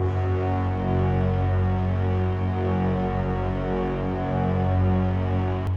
piano-sounds-dev
Orchestra